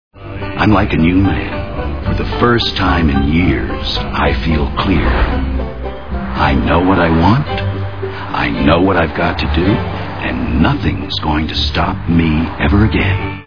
About Schmidt Movie Sound Bites